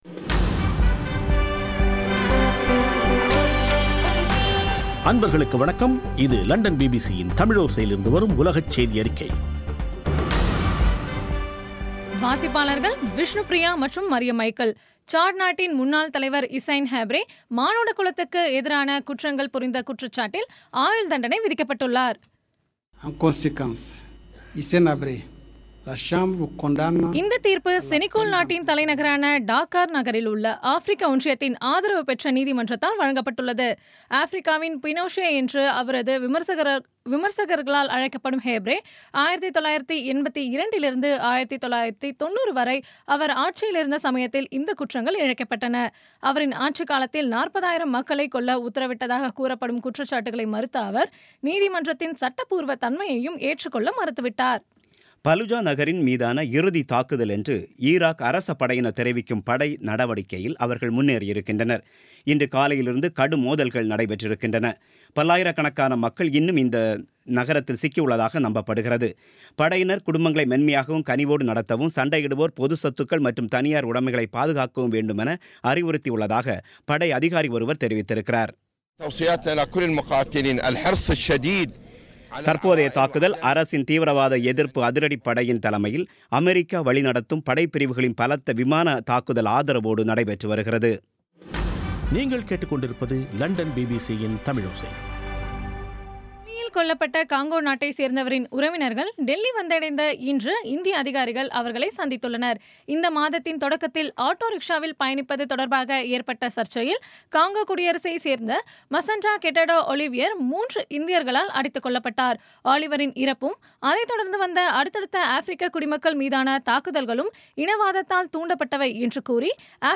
இன்றைய பி பி சி தமிழோசை செய்தியறிக்கை (30/05/2016)